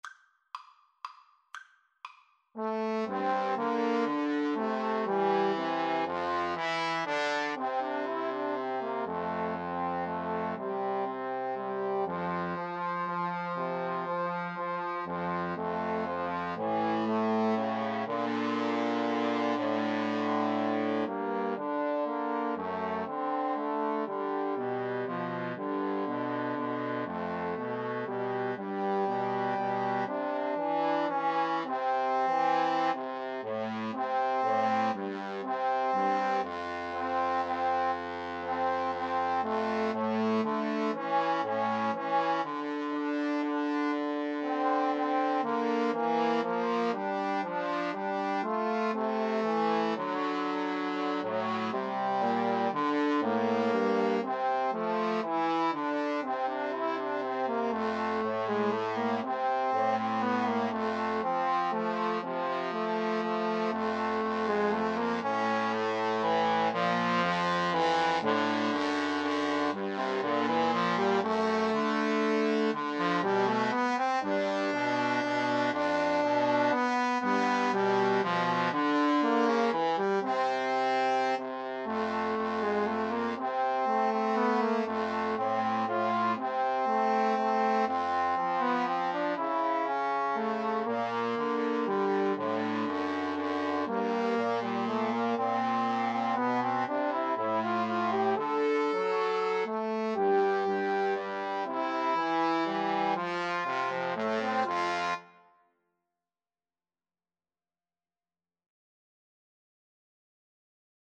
Trombone 1Trombone 2Trombone 3
= 120 Tempo di Valse = c. 120
3/4 (View more 3/4 Music)